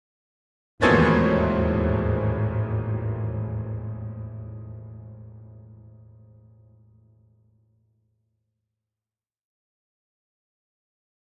Piano Danger Chord Type B - Higher